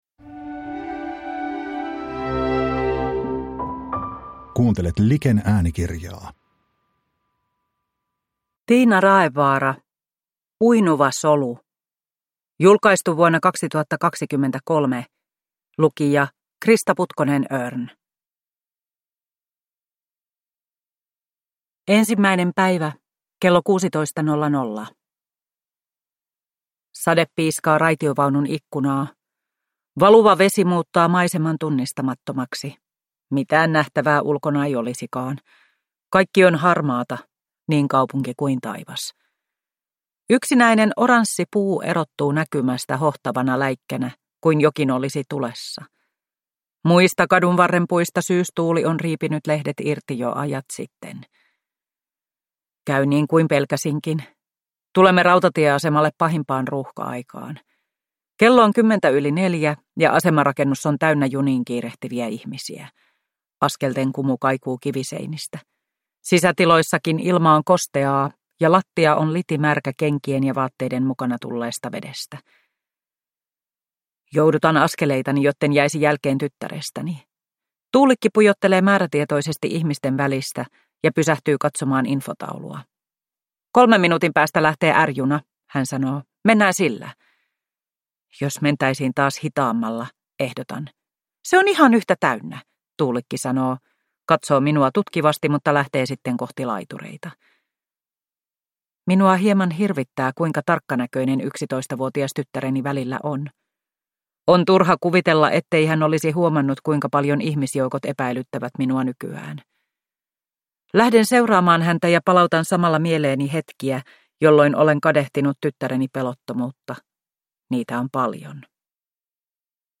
Uinuva solu – Ljudbok – Laddas ner